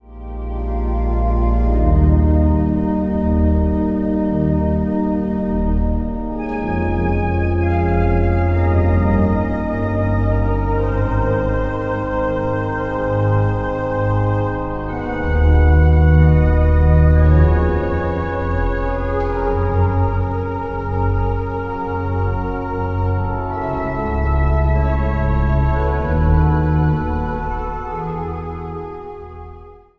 Meditatieve psalmen
Evangelisch Lutherse Kerk | Den Haag
Instrumentaal | Orgel